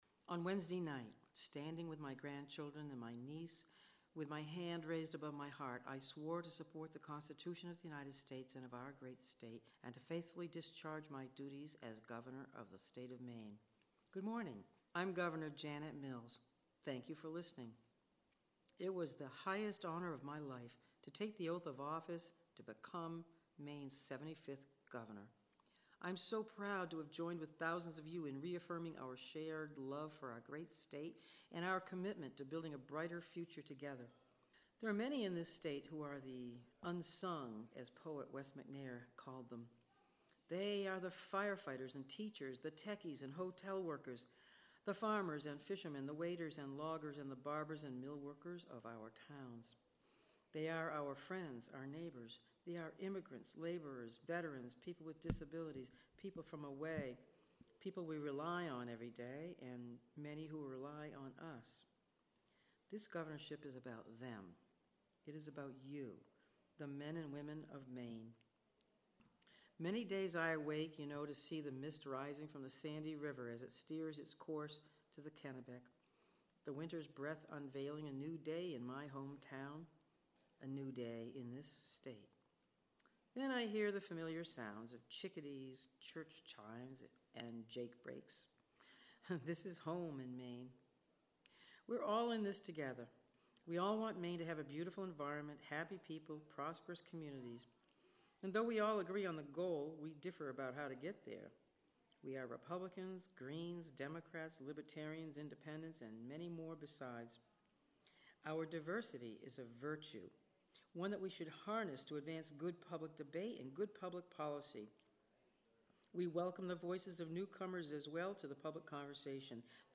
Radio Address: Governor Mills to the people of Maine: “Welcome Home”
Governor Mills Radio Address, January 4, 2019 (mp3, Audio)